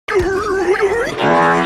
Skulker Fart Sound Effect Free Download
Skulker Fart